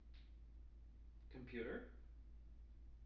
wake-word
tng-computer-371.wav